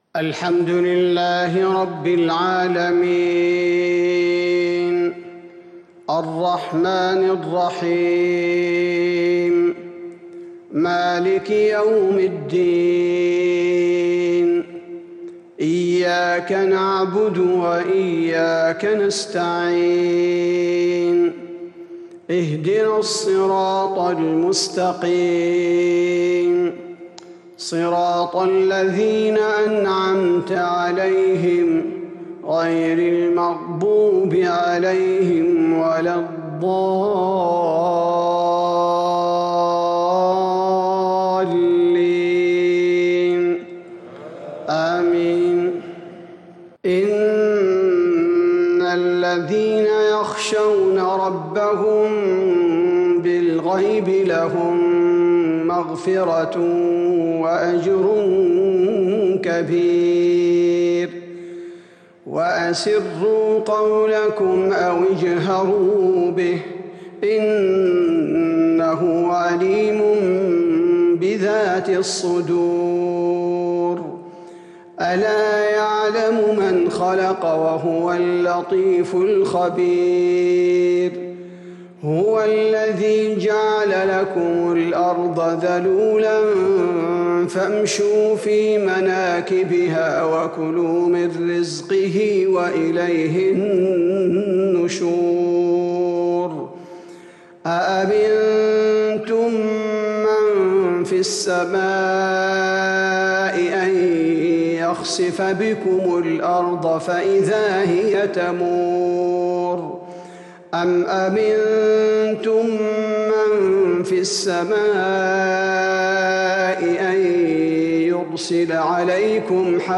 صلاة العشاء للقارئ عبدالباري الثبيتي 9 جمادي الأول 1442 هـ